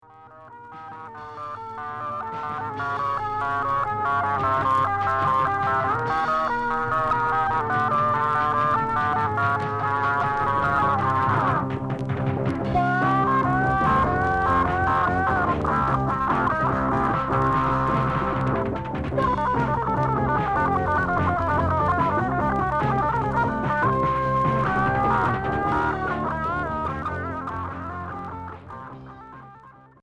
Progressif Unique 45t